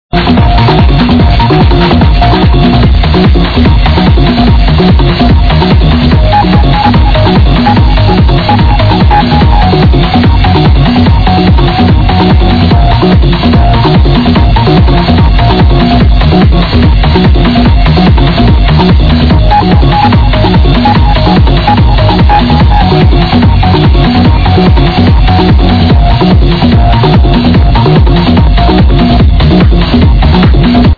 Thumbs up techno tune id ?